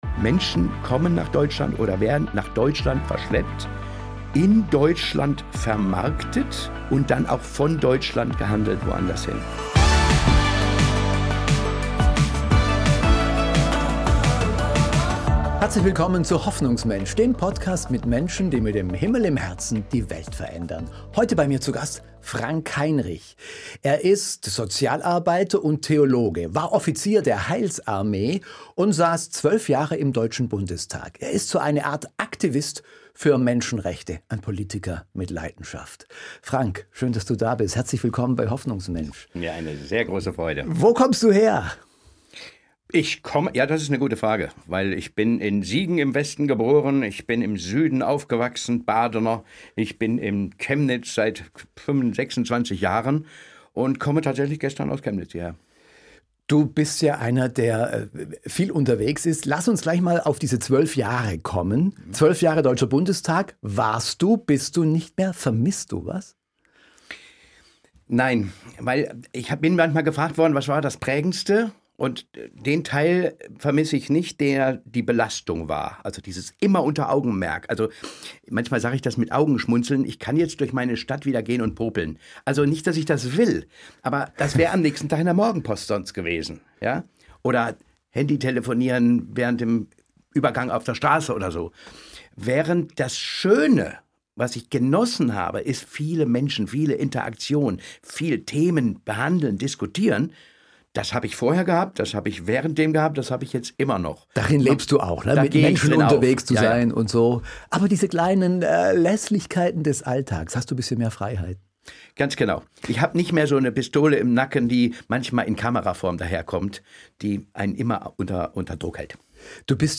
im Podcast-Gespräch